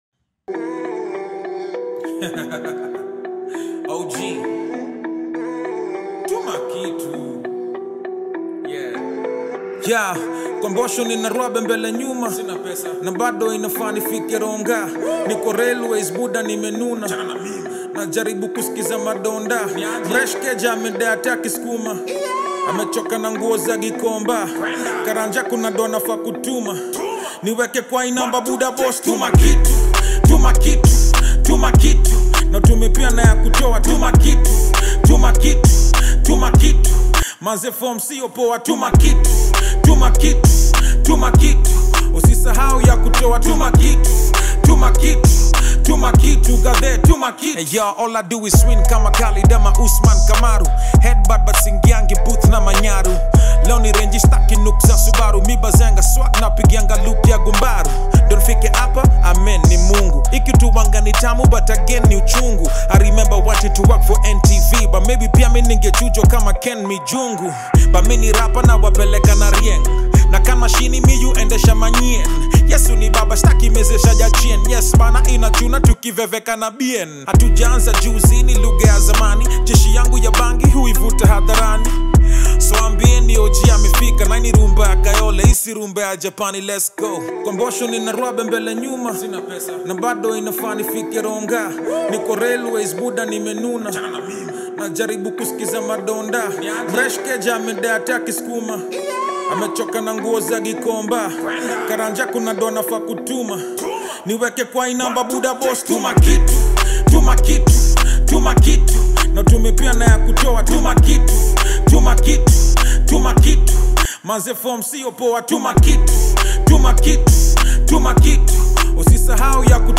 Kenyan rapper